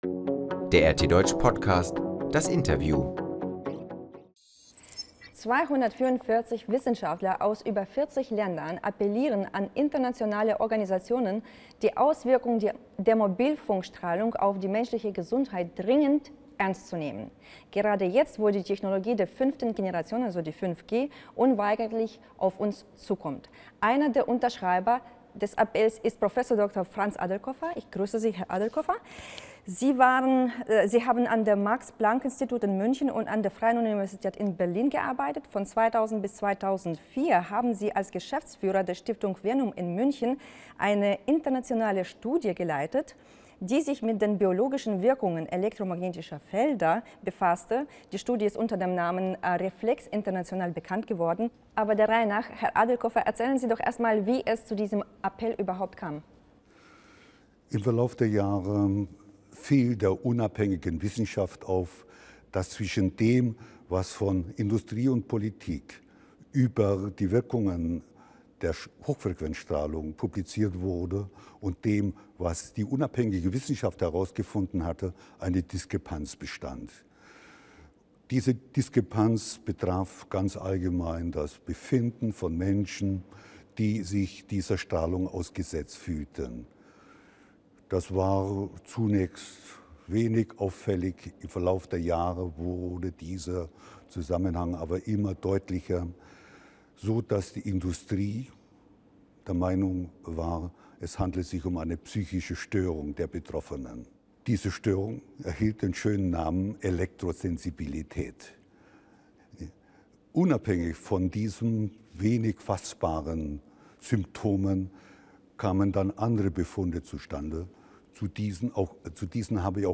Wissenschaftler im Interview: Schädliche Folgen der Mobilfunkstrahlung werden verborgen